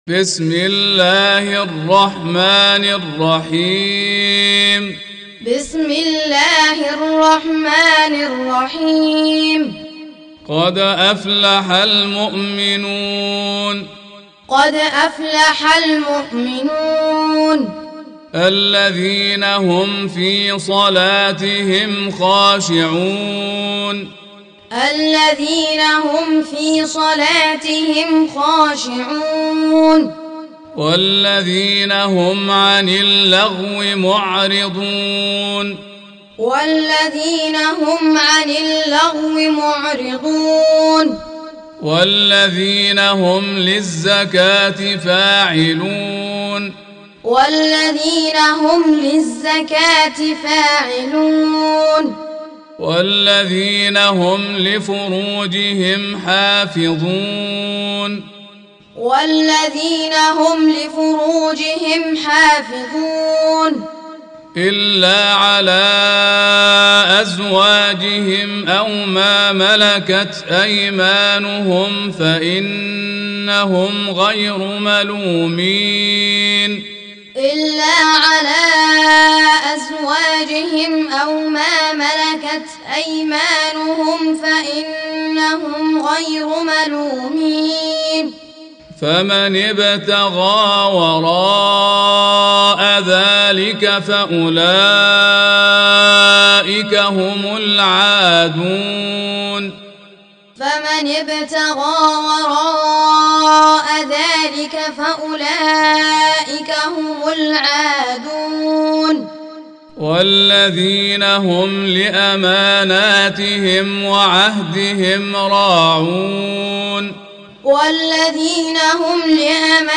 Surah Repeating تكرار السورة Download Surah حمّل السورة Reciting Muallamah Tutorial Audio for 23. Surah Al-Mu'min�n سورة المؤمنون N.B *Surah Includes Al-Basmalah Reciters Sequents تتابع التلاوات Reciters Repeats تكرار التلاوات